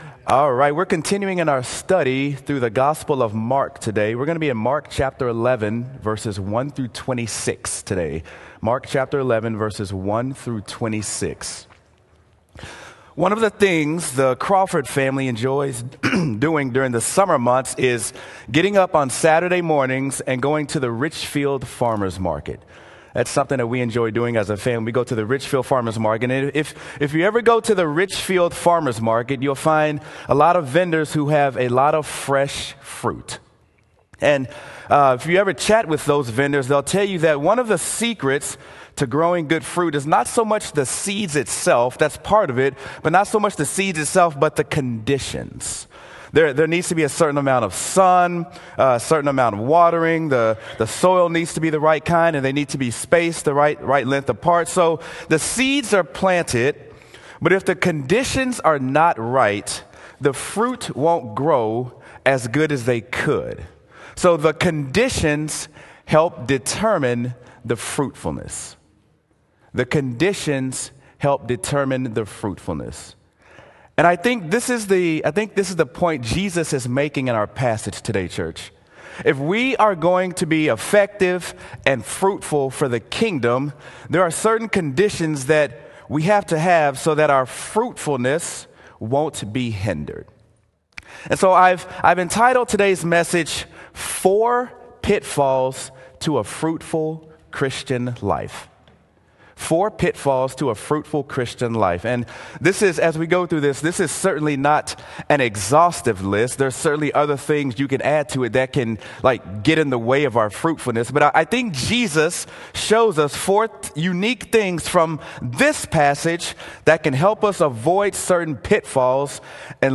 Sermon: Mark: Four Pitfalls to a Fruitful Christian Life